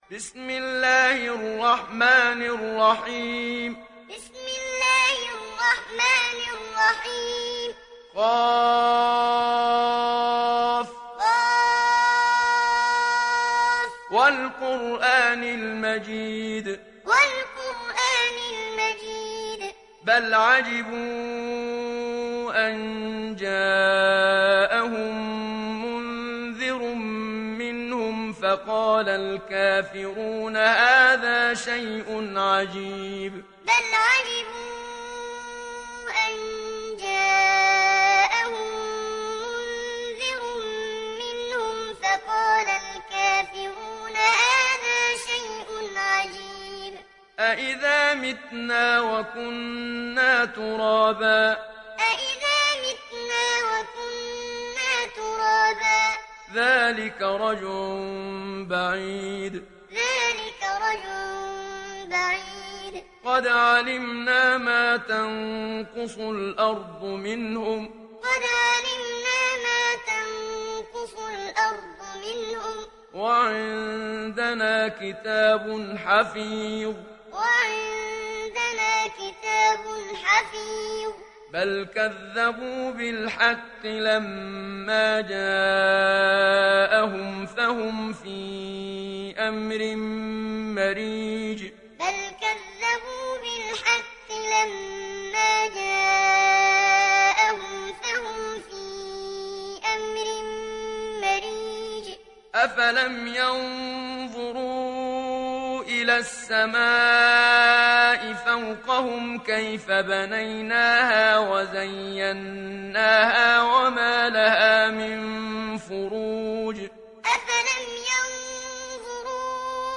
Surah Qaf mp3 Download Muhammad Siddiq Minshawi Muallim (Riwayat Hafs)
Surah Qaf Download mp3 Muhammad Siddiq Minshawi Muallim Riwayat Hafs from Asim, Download Quran and listen mp3 full direct links
Muallim